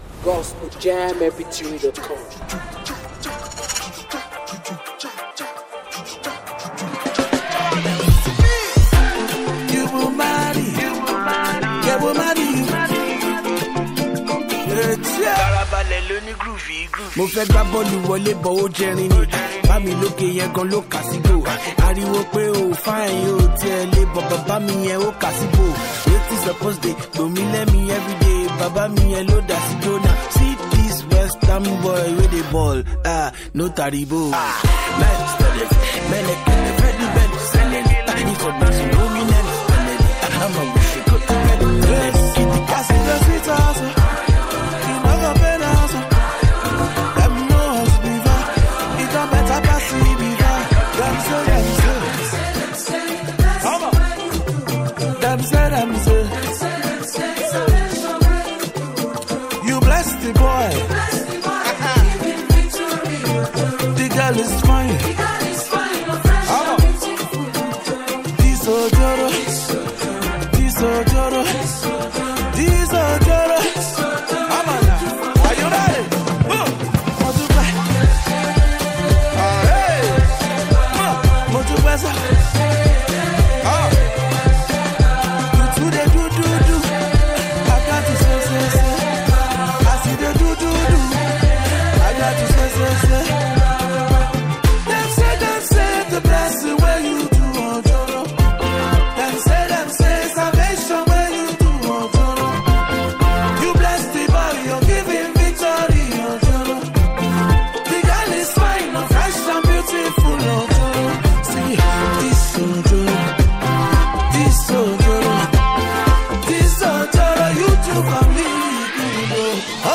creative rap delivery